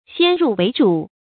注音：ㄒㄧㄢ ㄖㄨˋ ㄨㄟˊ ㄓㄨˇ
先入為主的讀法